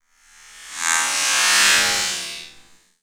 SCIFI_Sweep_06_mono.wav